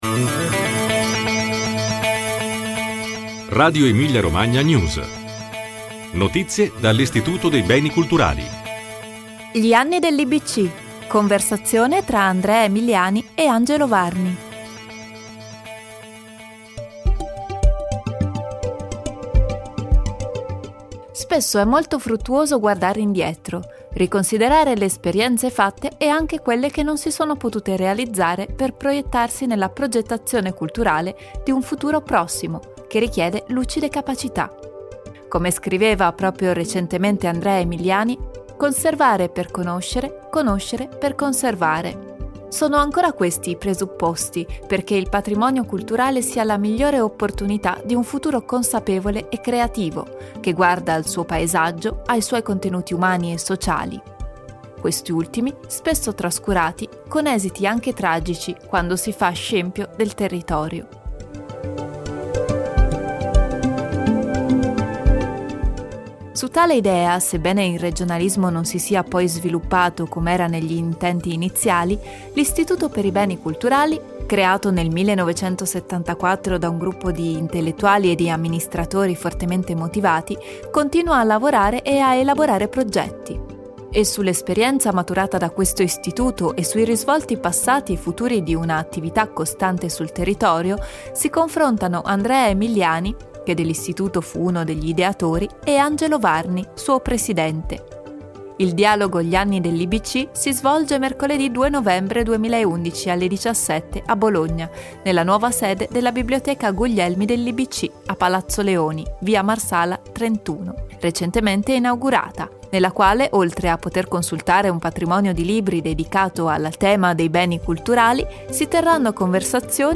Gli anni dell’Ibc. Conversazione